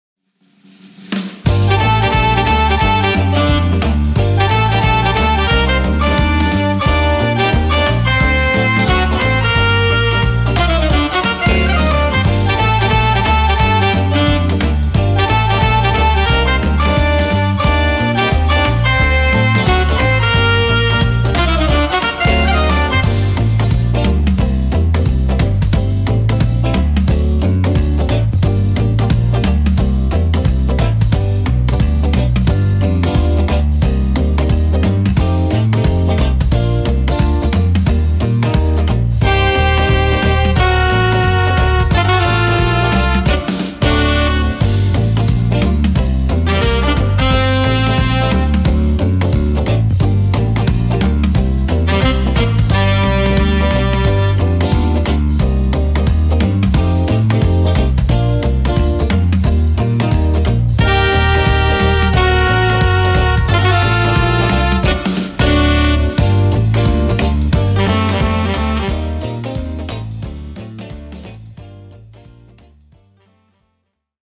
Жаль только что это демо версия